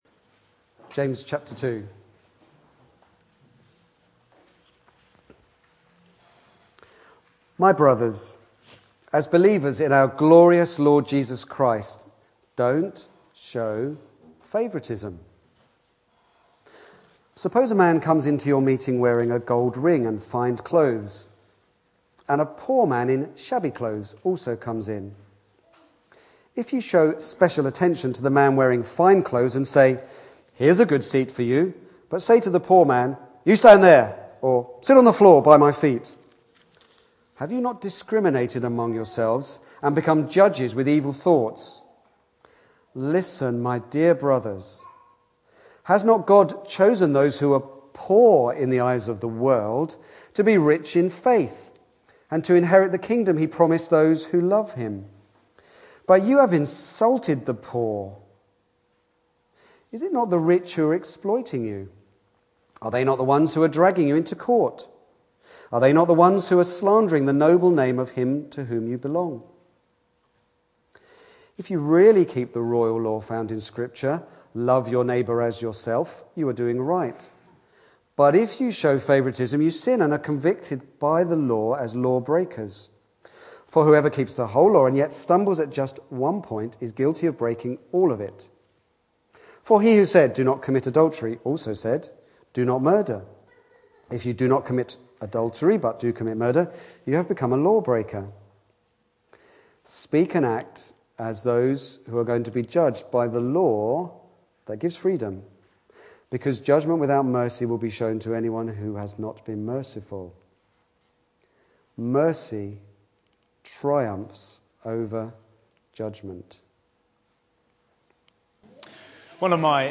Back to Sermons The law of love